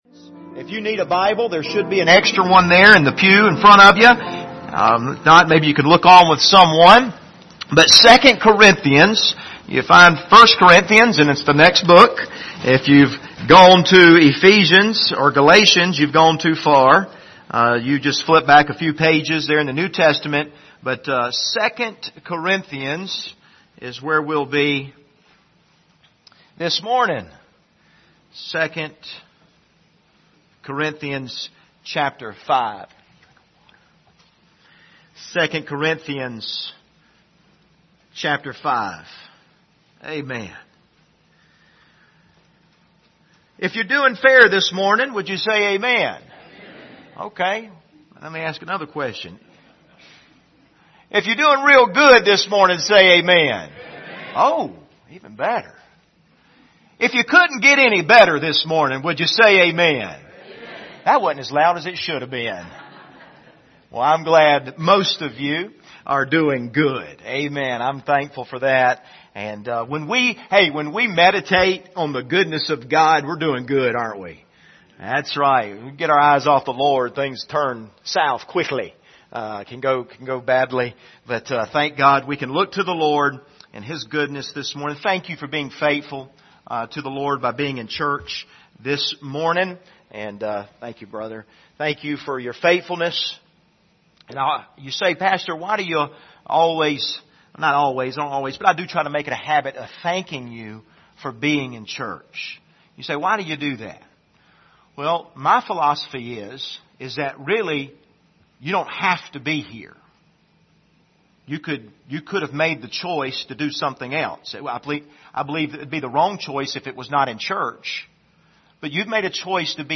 Passage: 2 Corinthians 5:17-21 Service Type: Sunday Morning